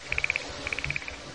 ParrotFrog_sound.mp3